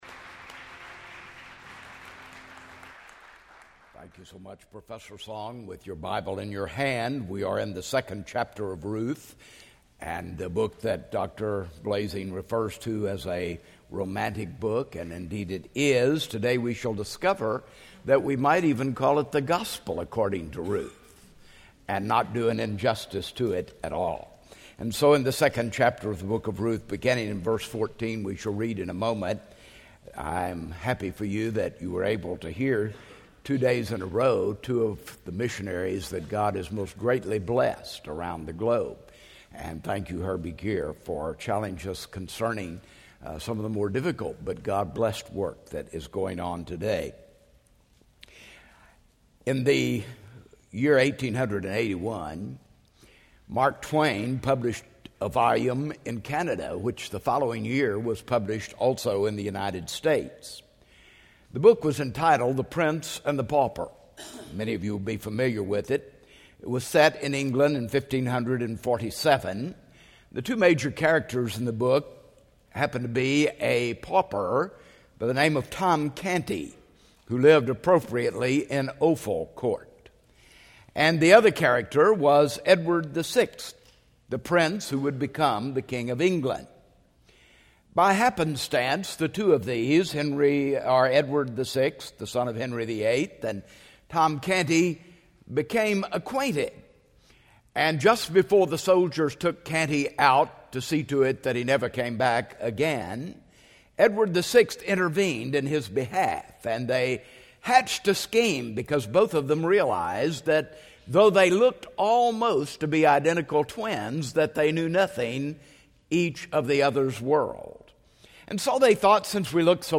in SWBTS Chapel on Wednesday February 17, 2010